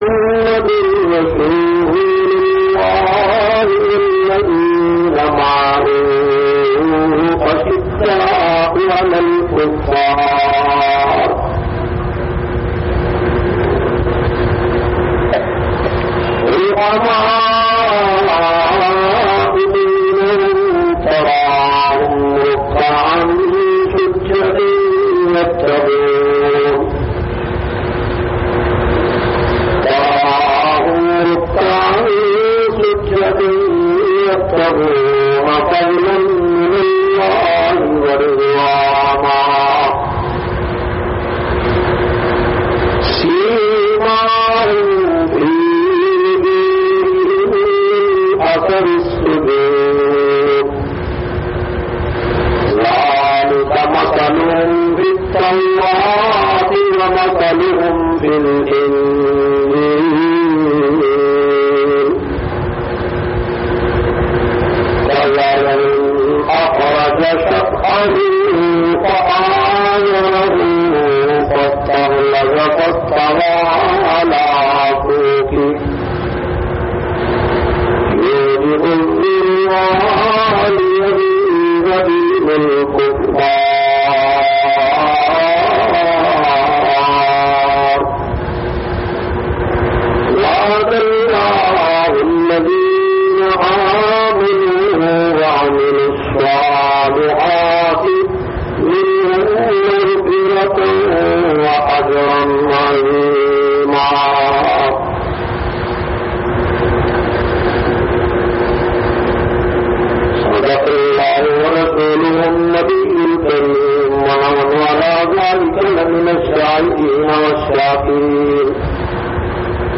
451- Shan e Sahaba Eid ul Adha khutba Jumma Jamia Masjid Muhammadia Samandri Faisalabad.mp3